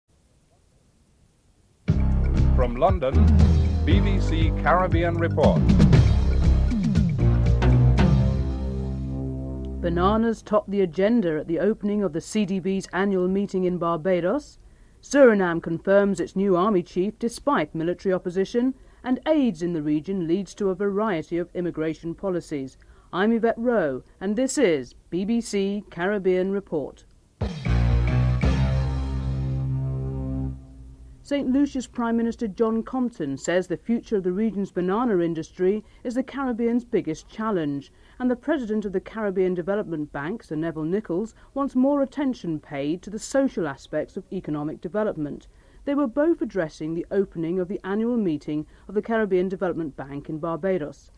Headlines with anchor